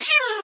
sounds / mob / cat / hitt3.ogg
hitt3.ogg